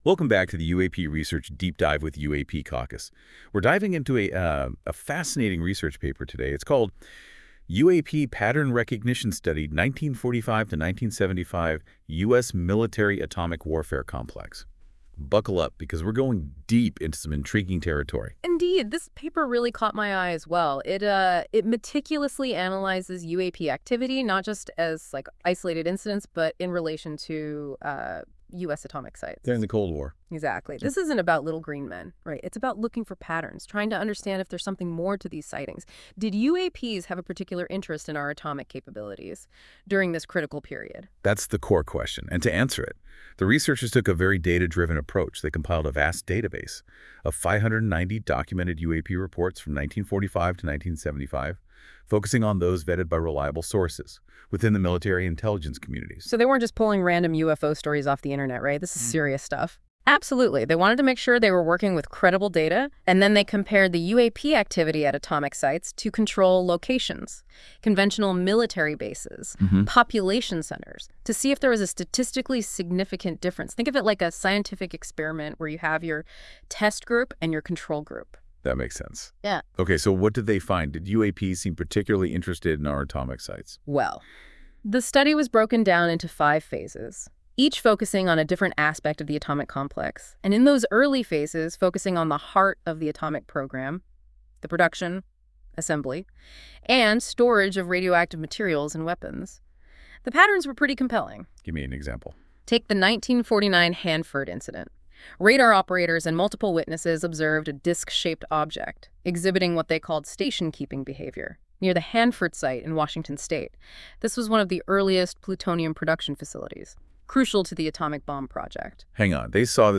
Powered by NotebookLM. This AI-generated audio may not fully capture the research's complexity.
Audio Summary